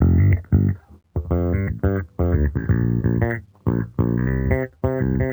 Index of /musicradar/sampled-funk-soul-samples/90bpm/Bass
SSF_JBassProc2_90G.wav